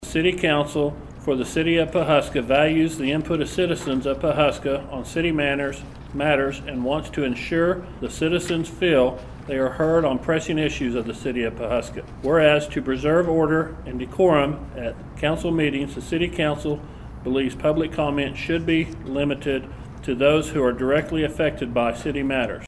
On Tuesday, that law had been written up and Mayor Steve Tolson
reads part of the resolution.